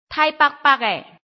臺灣客語拼音學習網-客語聽讀拼-南四縣腔-入聲韻
拼音查詢：【南四縣腔】bag ~請點選不同聲調拼音聽聽看!(例字漢字部分屬參考性質)